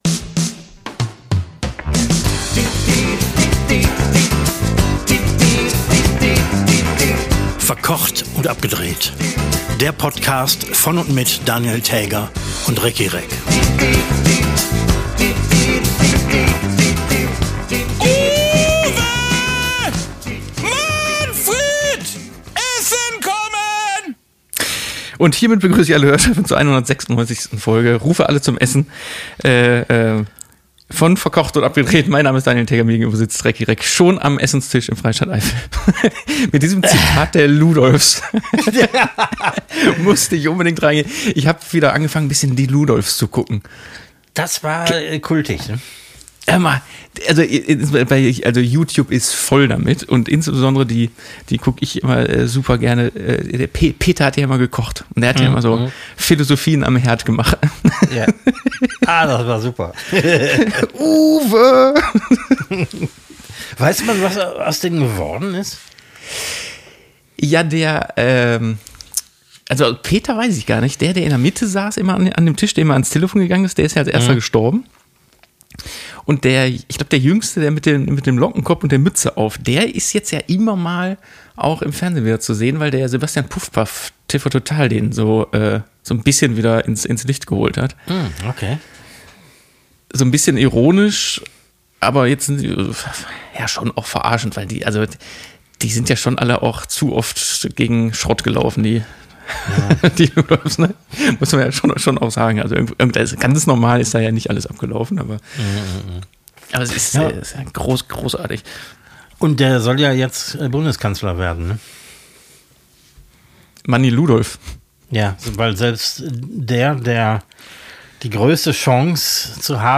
Das geübte Auge stellt zudem fescht, dath* dem Eifler Koch ein paar schirurgische Fäden auth* dem Mundwinkel hängen und ihm „sss“- und „schsch“-Laute deutlisch bether* dem Mund entfleuschen….